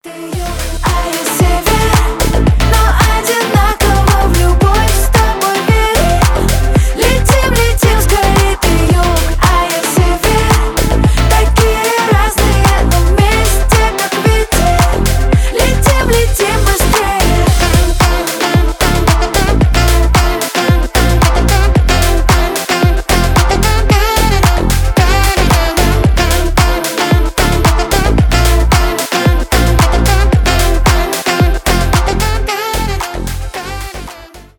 Русские » Поп